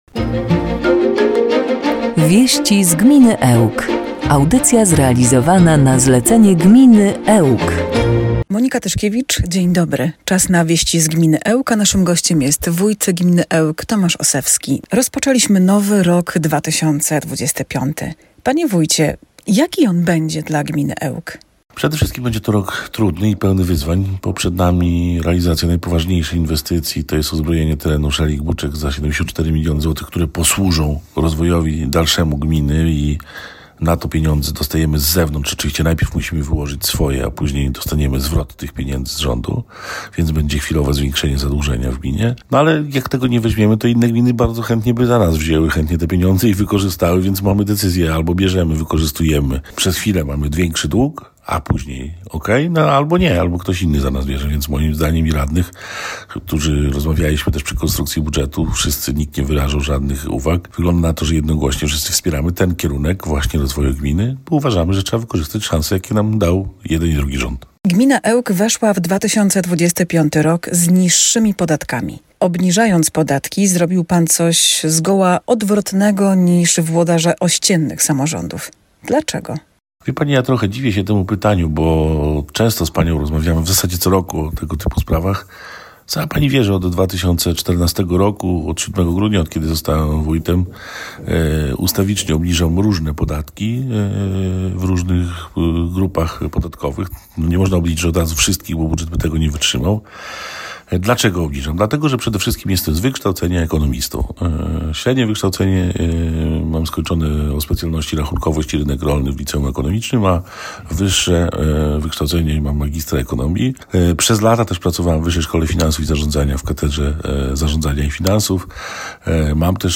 Realizacja najważniejszych inwestycji w 2025 roku w gminie Ełk, obniżka podatków i nowy podział sołectw – to tematy, które poruszono w audycji „Wieści z gminy Ełk”. Gościem Radia 5 był Tomasz Osewski, wójt gminy Ełk.